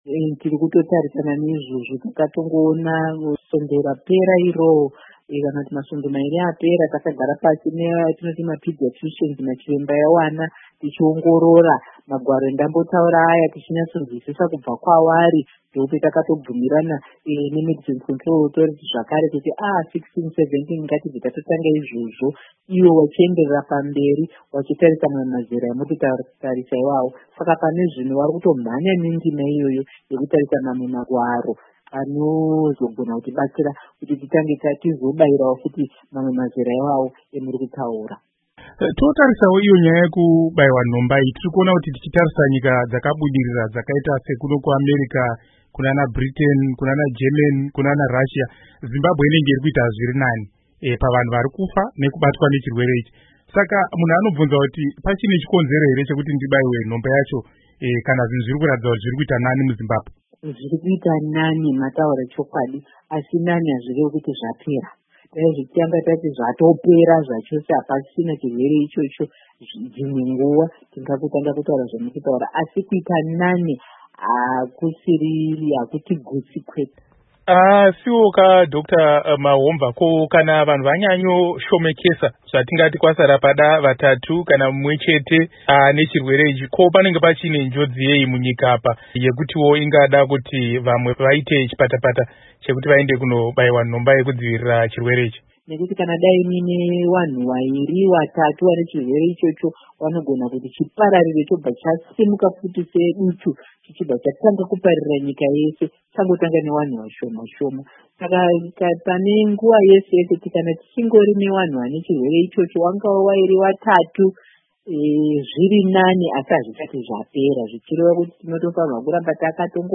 Hurukuro naDr Agnes Mahomva